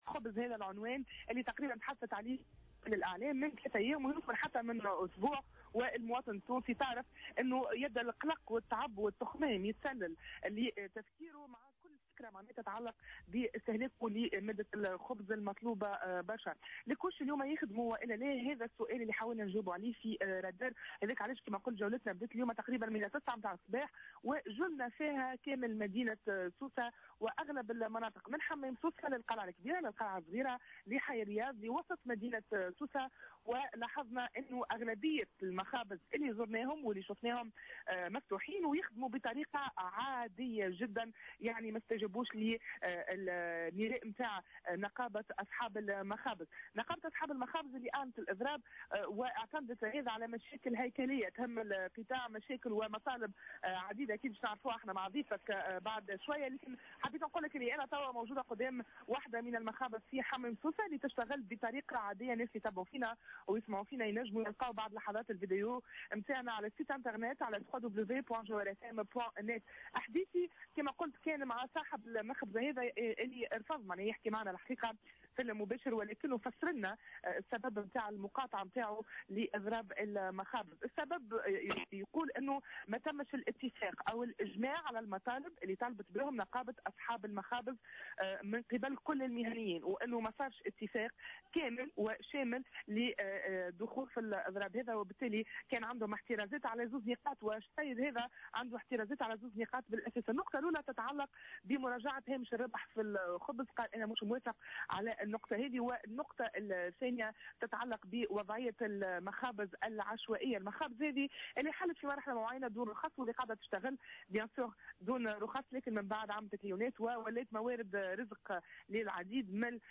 تناول اليوم فريق "الرادار" موضوع إضراب أصحاب المخابز، الذي دعت له الغرفة الوطنية لأصحاب المخابز، وتنقل إلى عدة مخابز بحمام سوسة والقلعة الكبرى والقلعة الصغرى وحي الرياض ووسط مدينة سوسة.